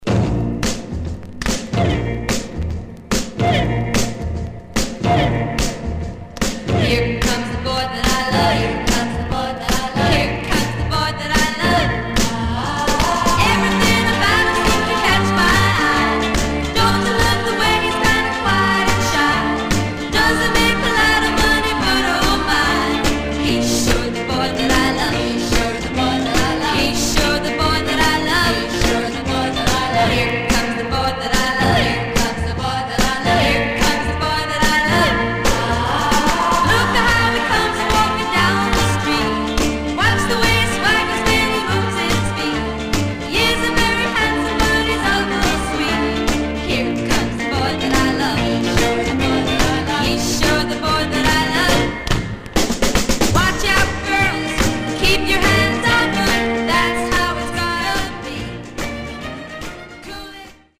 Mono
White Teen Girl Groups